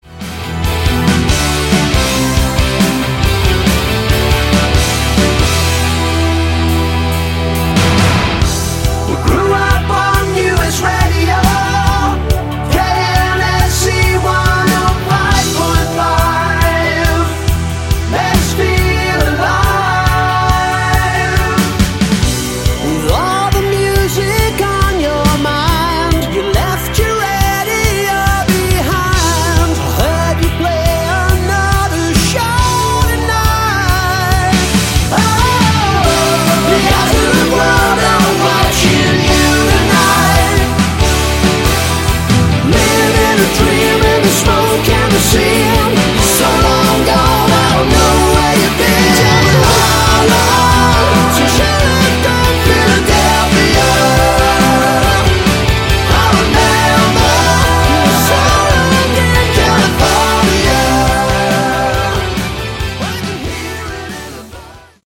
Category: AOR
keyboards, vocals
guitars
bass, vocals
drums, percussion